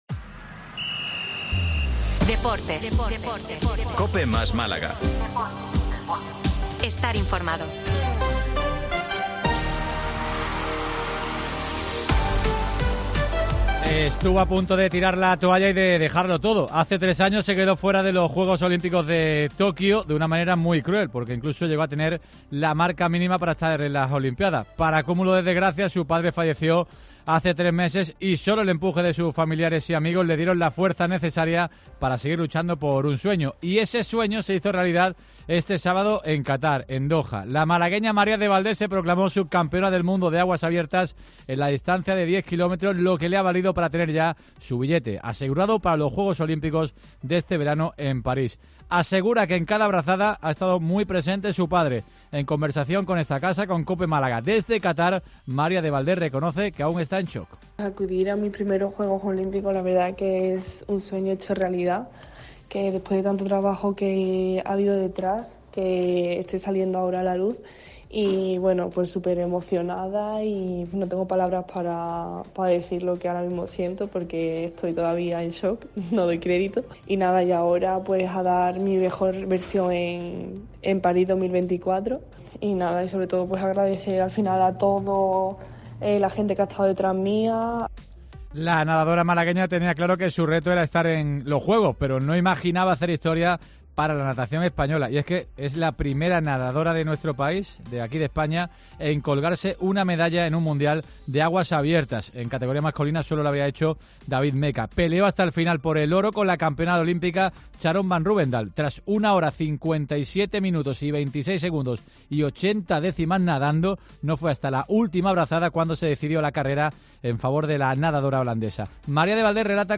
En conversación con COPE Málaga desde Qatar, María de Valdés reconoce que está en shock: “Acudir a mis primeros juegos olímicos es un sueño hecho realidad y saca a la luz el duro trabajo que hay detrás.